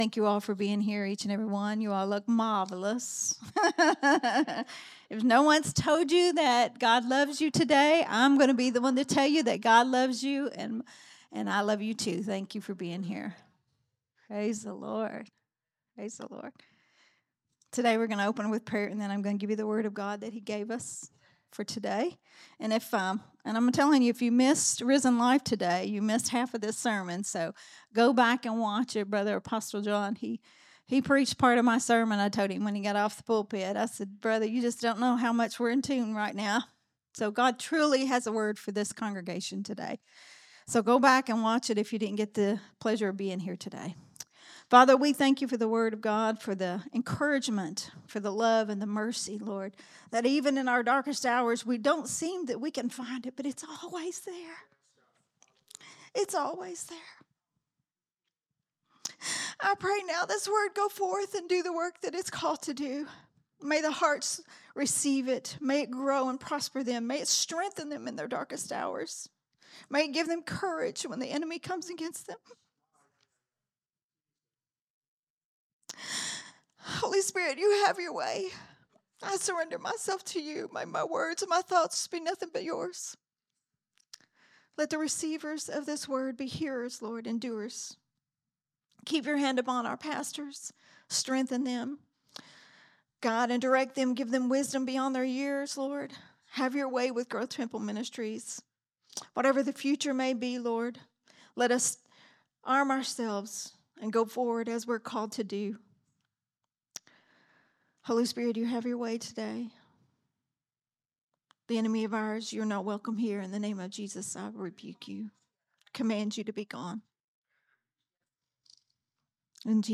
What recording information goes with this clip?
Sunday Morning Worship Service Growth Temple Ministries